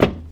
STEPS Wood, Hollow, Walk 05.wav